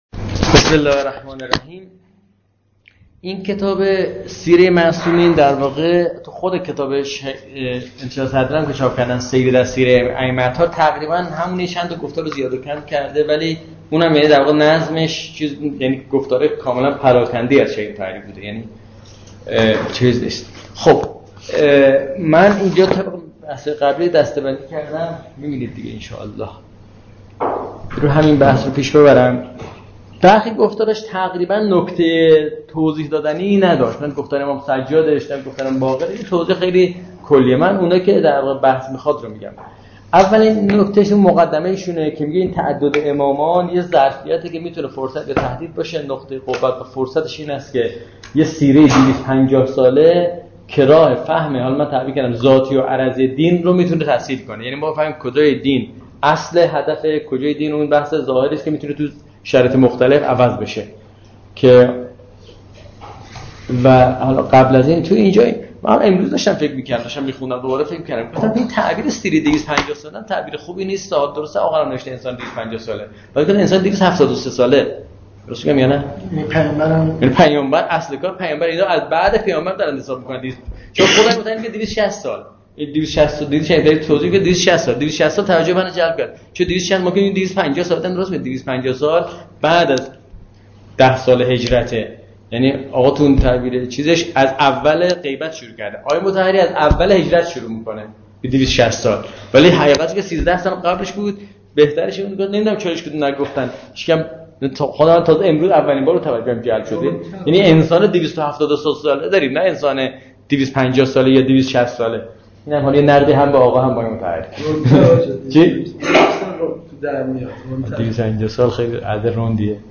در این جلسات ابتدا بنده نکاتی درباره چارچوب فکری و زوایای مهم بحث در کتاب مورد نظر مطرح می کنم و سپس جلسه به صورت پرسش و پاسخ ادامه می یابد.